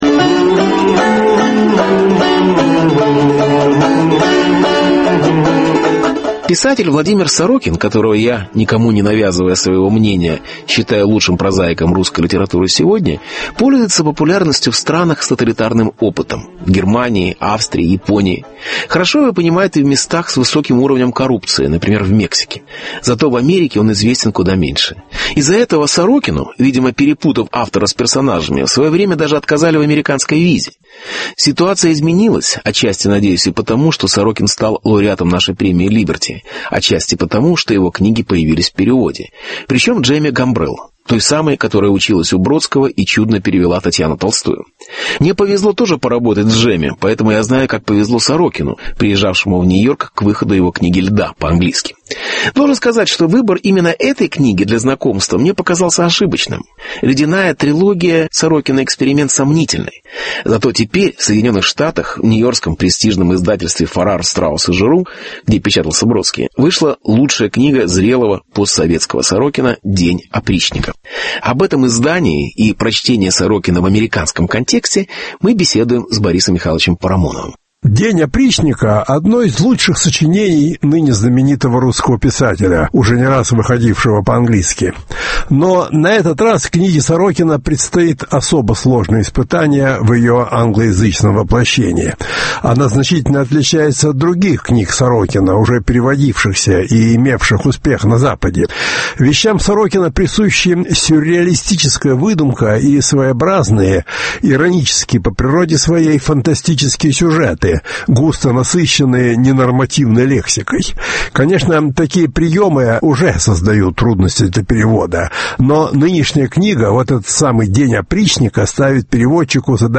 Сорокин в Америке. Беседа с Борисом Парамоновым.